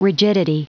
Prononciation du mot : rigidity
rigidity.wav